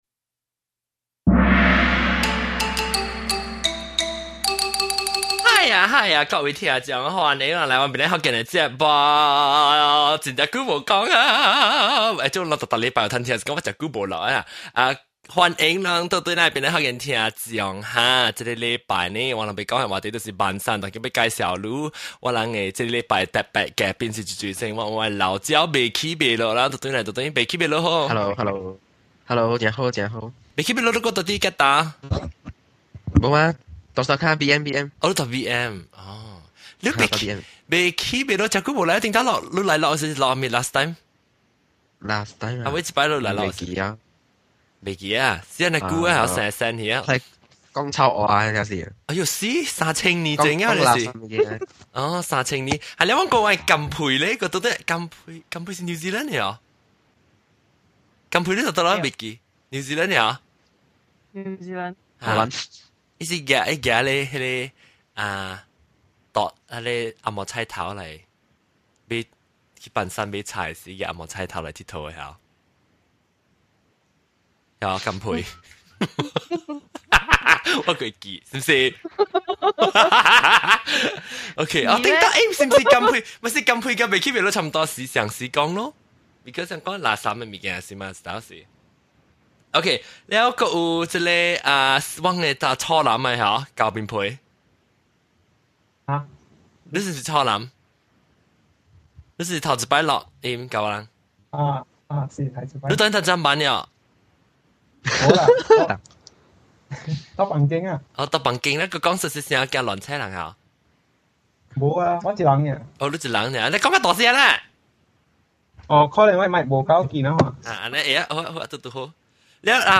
I was wondering how many people still go the wet market, especially the younger generations. Find out the results from our live show poll and our special guests.